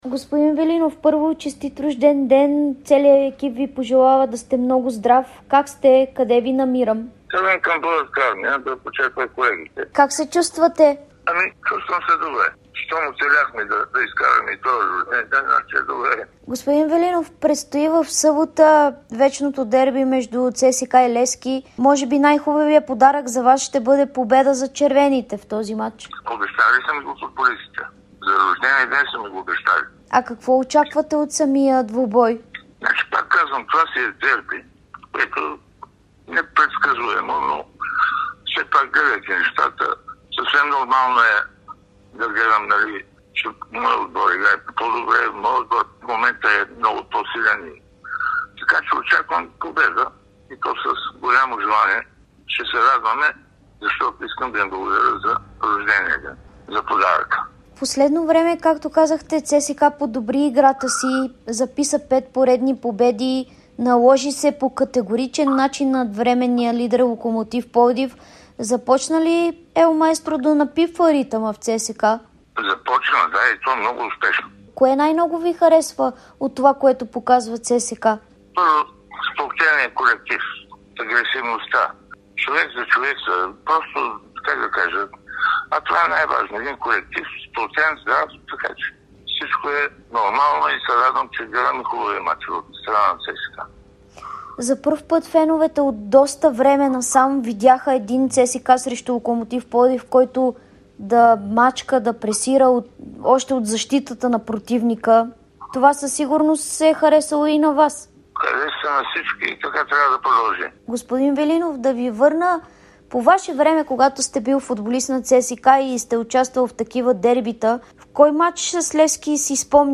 Легендарният вратар на ЦСКА Георги Велинов даде ексклузивно интервю пред Дарик радио и dsport навръх своя 66-и рожден ден.